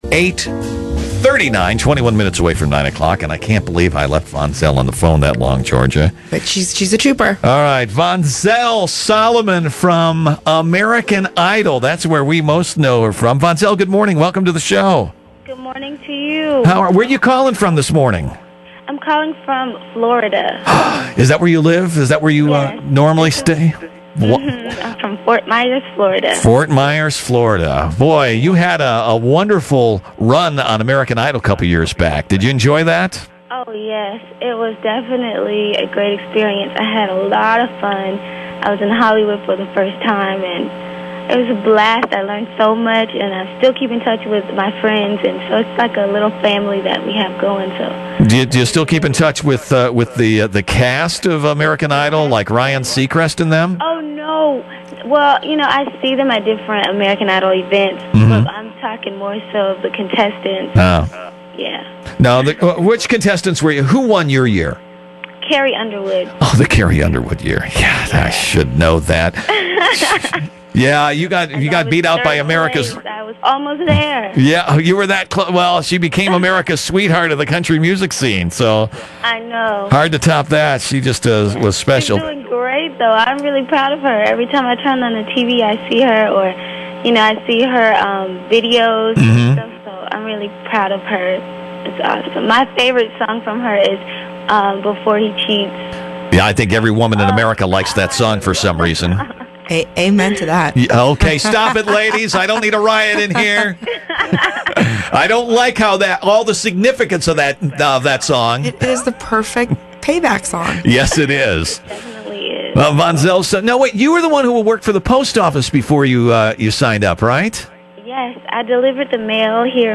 Another Interview from 2010 — Vonzell Solomon